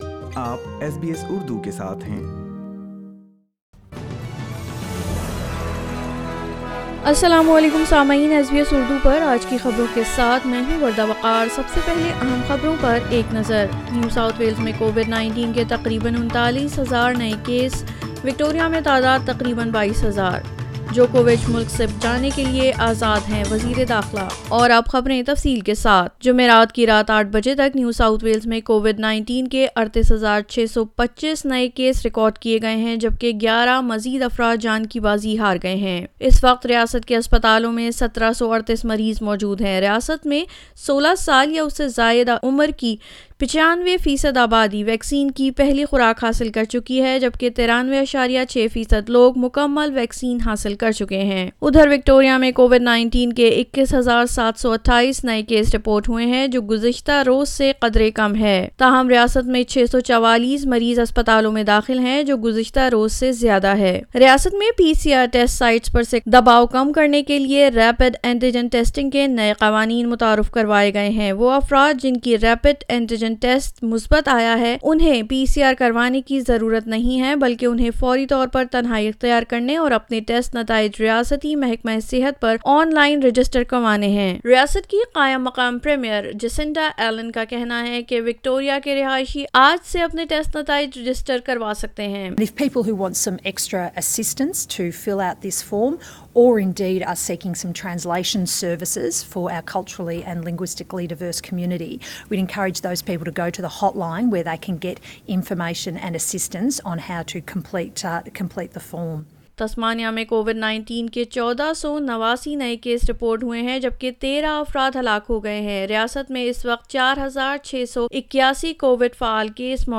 SBS Urdu News 07 Jaunary 2022